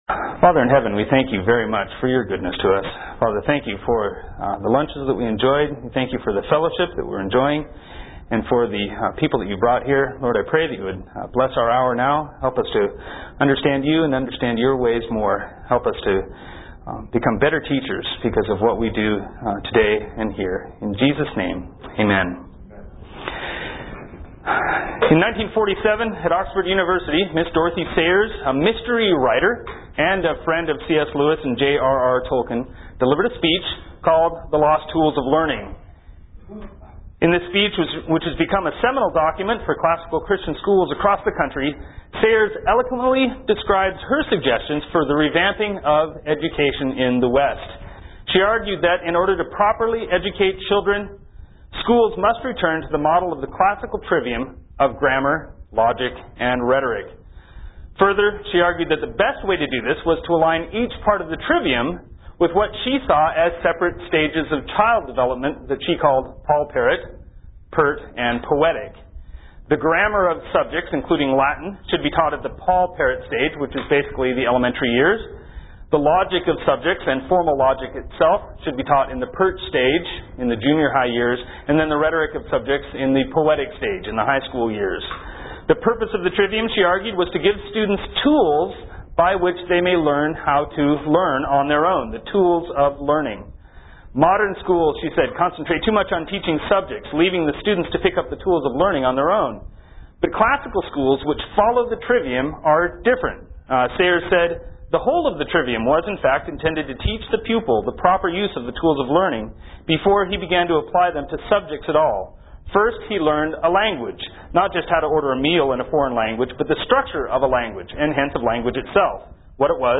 2003 Workshop Talk | 1:03:15 | 7-12, Logic